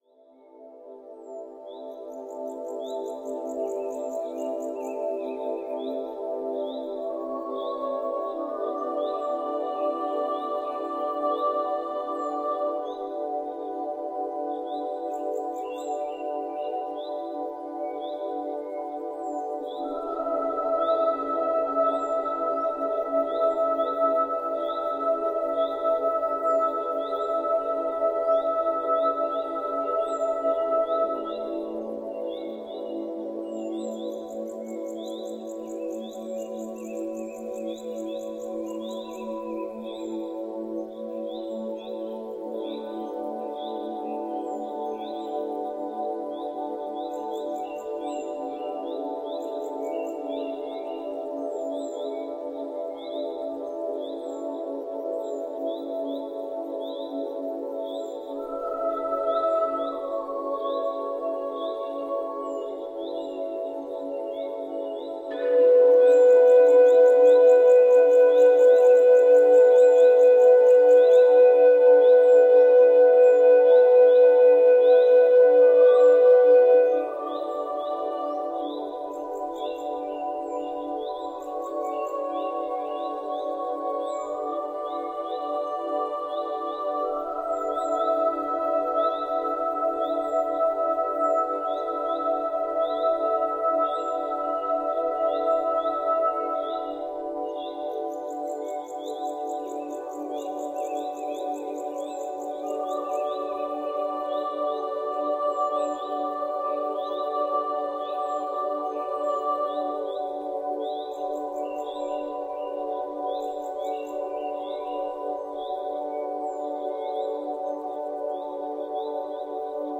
Мелодии для умиротворения и спокойствия